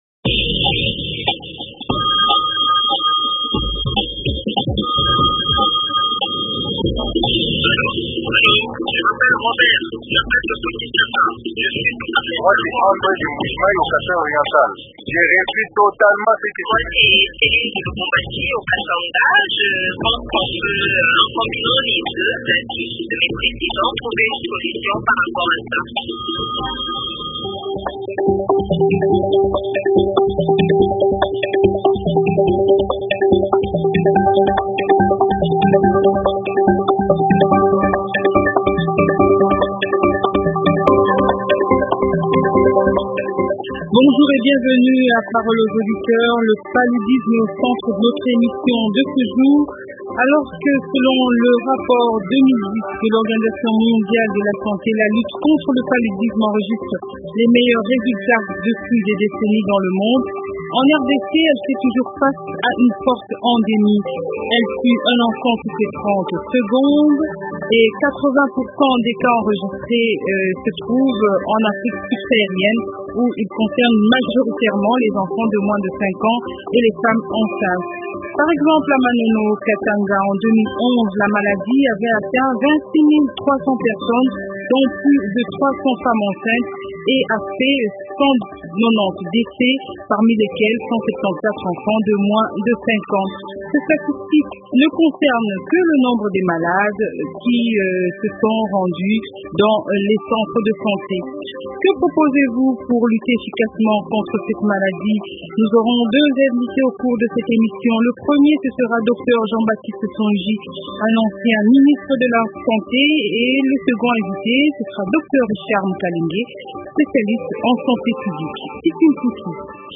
Le paludisme est au centre de notre émission du jour.